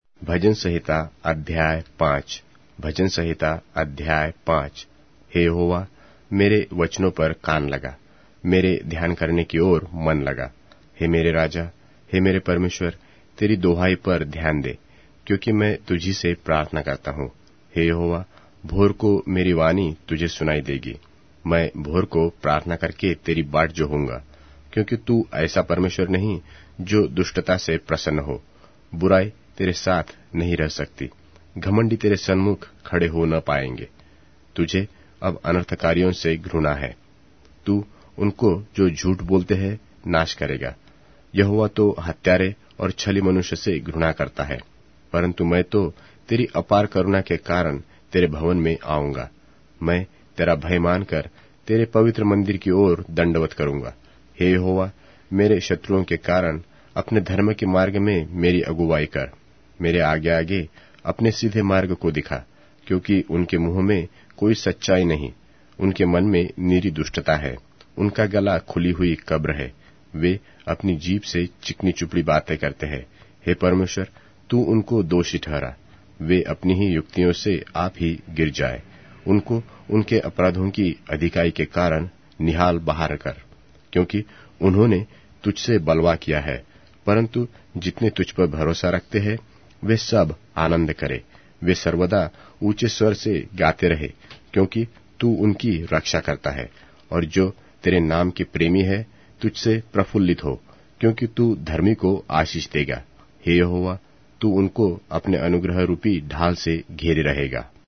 Hindi Audio Bible - Psalms 9 in Kjv bible version